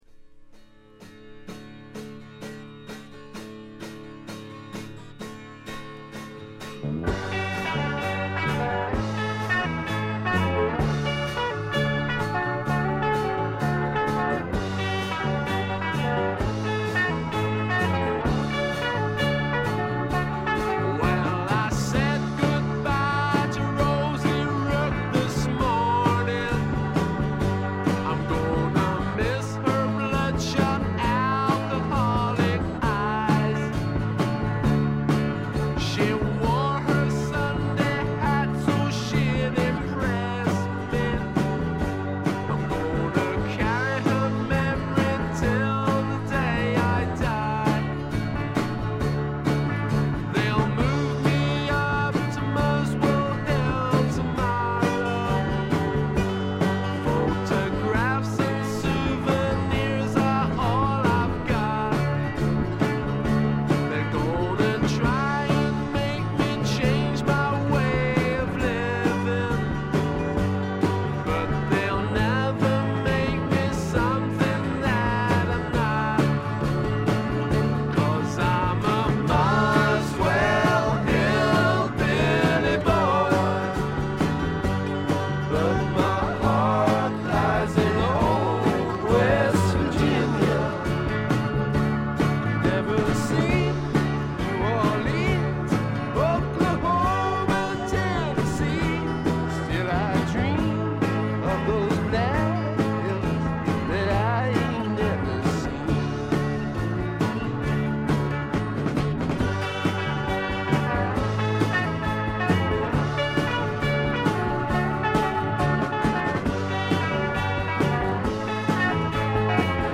これ以外は軽微なバックグラウンドノイズに散発的なプツ音少し。
試聴曲は現品からの取り込み音源です。